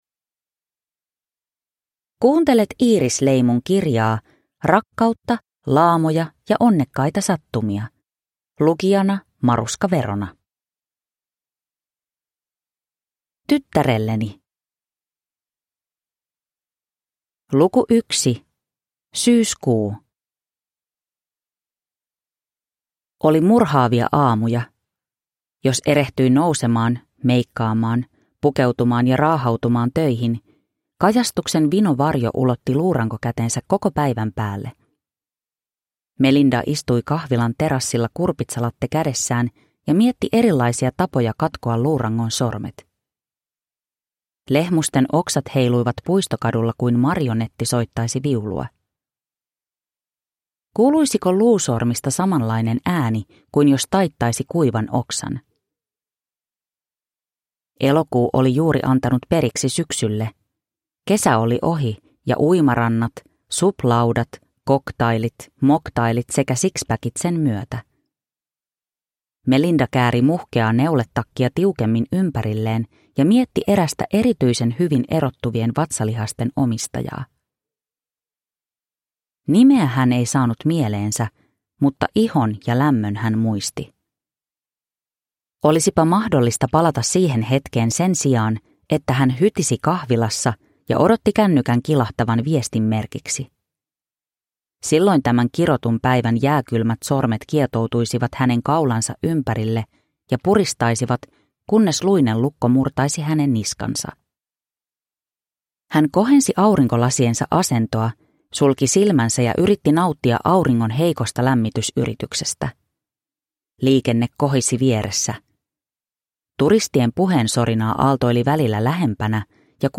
Rakkautta, laamoja ja onnekkaita sattumia (ljudbok) av Iiris Leimu